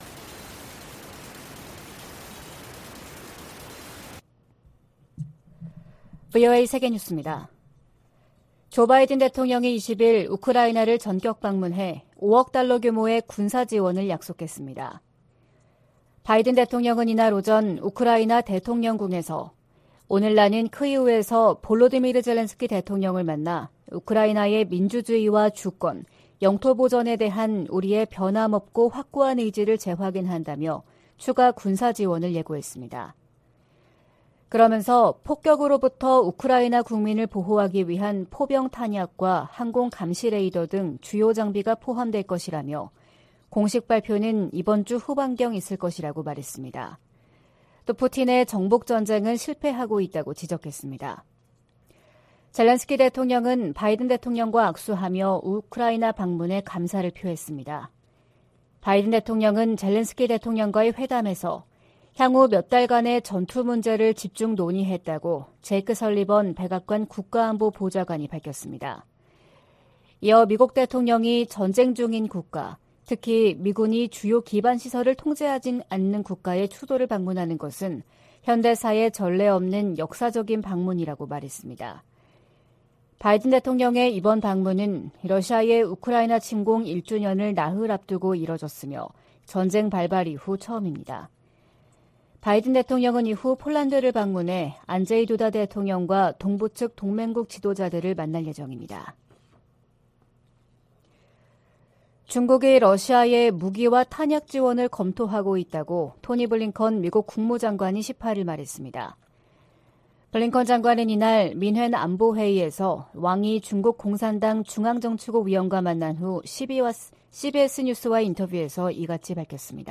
VOA 한국어 '출발 뉴스 쇼', 2023년 2월 21일 방송입니다. 북한이 '화성-15형' 대륙간탄도미사일(ICBM)을 쏜 지 이틀 만에 평안남도 숙천 일대에서 동해상으로 초대형 방사포를 발사했습니다. 미국과 한국, 일본 외교장관이 긴급 회동을 갖고 북한의 대륙간탄도미사일(ICBM) 발사를 규탄하면서 국제사회의 효과적인 대북제재 시행을 촉구했습니다. 한국은 북한의 대륙간탄도미사일 발사 등에 대해 추가 독자 제재를 단행했습니다.